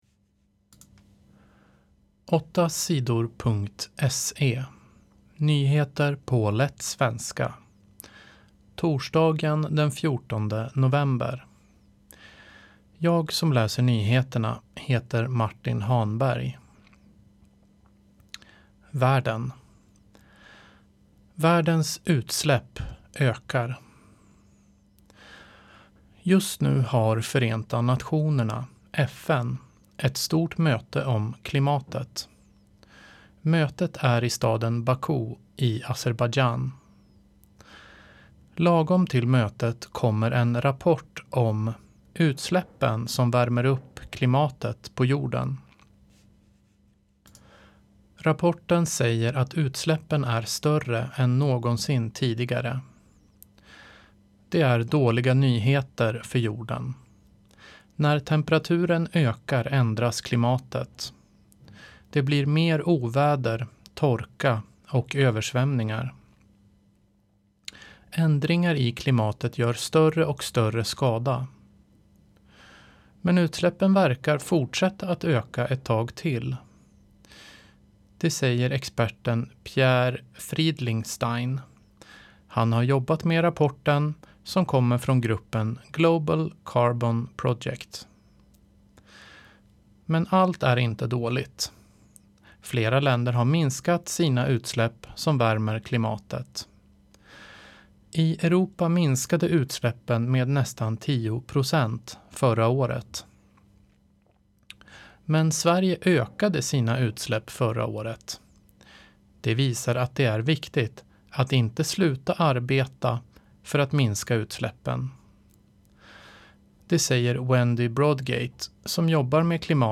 1 Nyheter på lätt svenska den 14 november 6:38